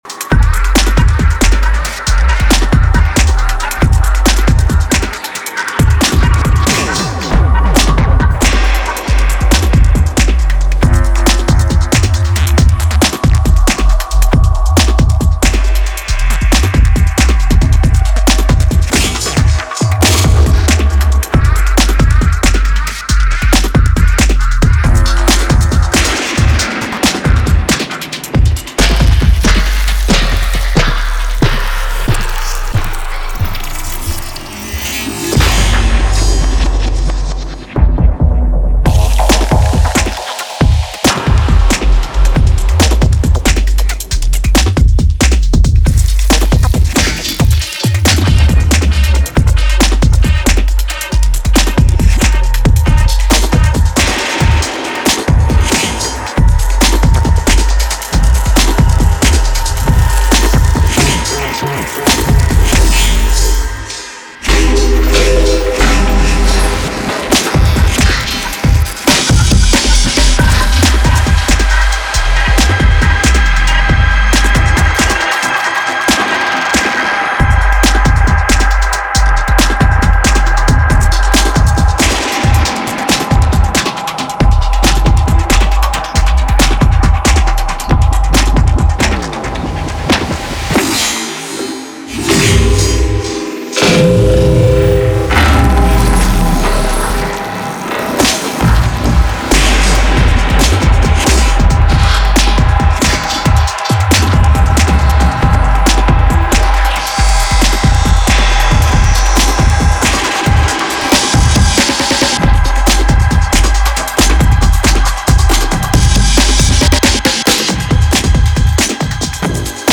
ベルリン郊外の湖畔で採取したフィールドレコーディングを中心に構成された
陽性なA面に比べるとかなりダークながら、たまらなくカッコいい仕上がり！